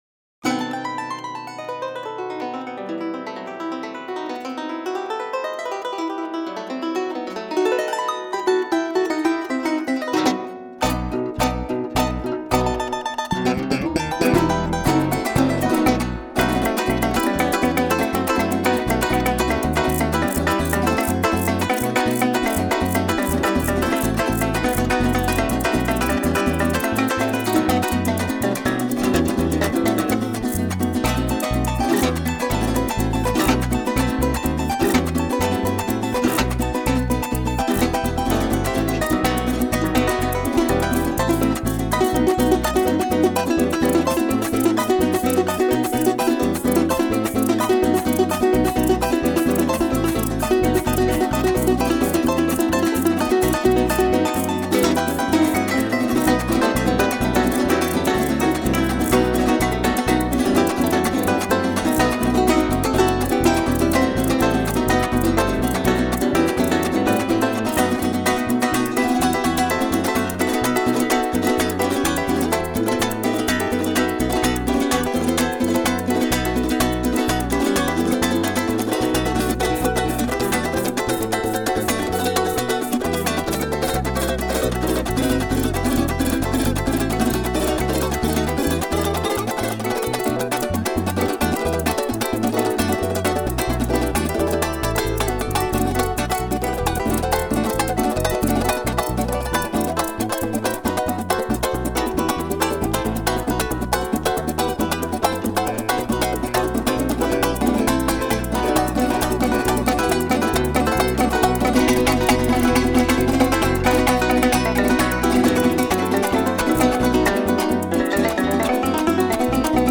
Pars Today- La música de América Latina.